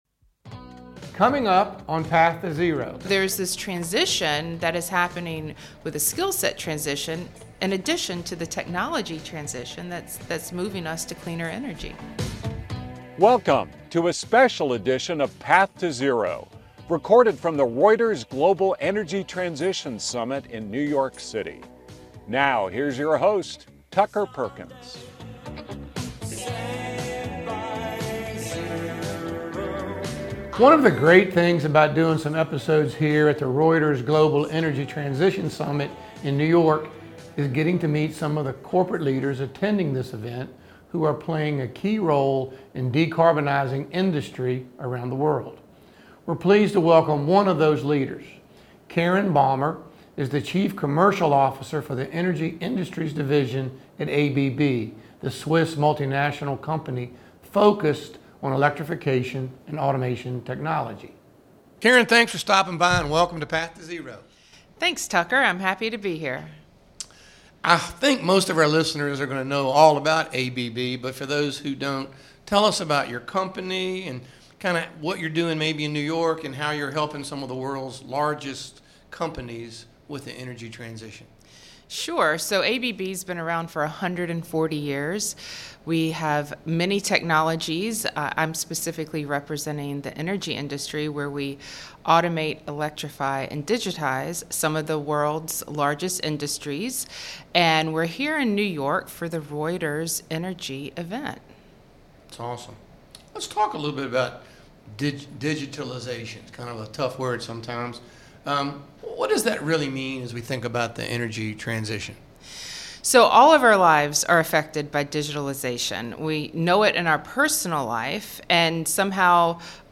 recorded from the Reuters Global Energy Transition event in New York City